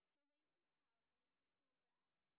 sp17_street_snr0.wav